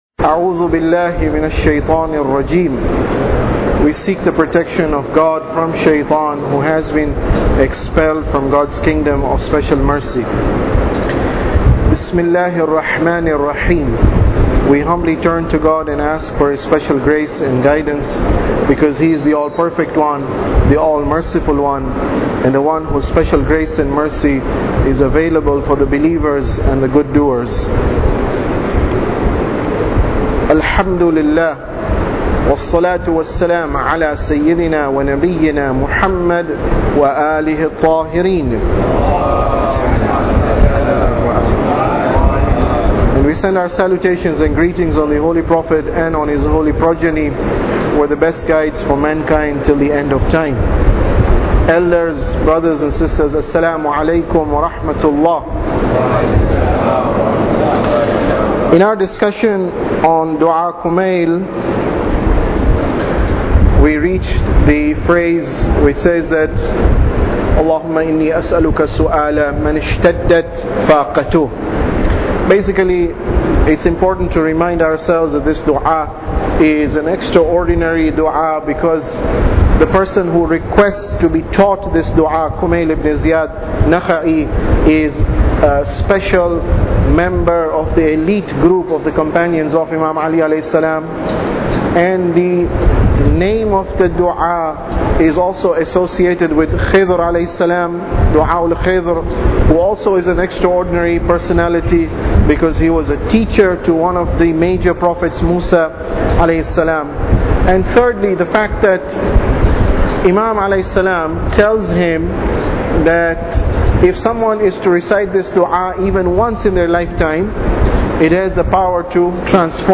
Tafsir Dua Kumail Lecture 26